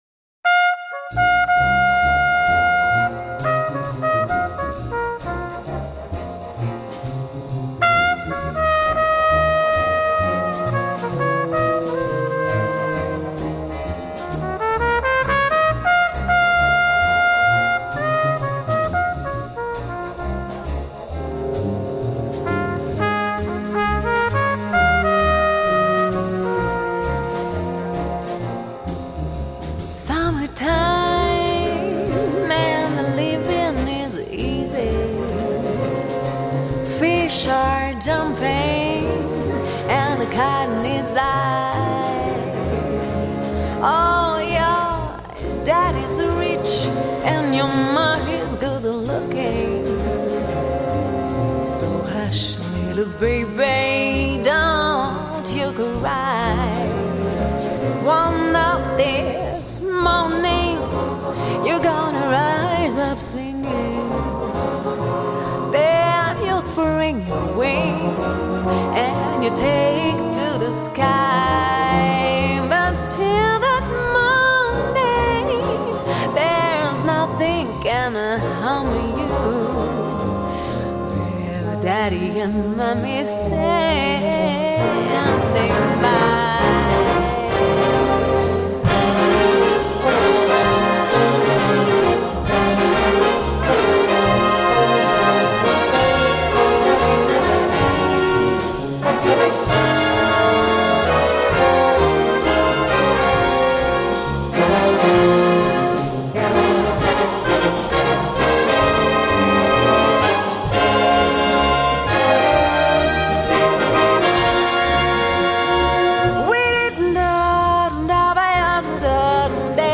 Gattung: für Solo Gesang und Blasorchester
Besetzung: Blasorchester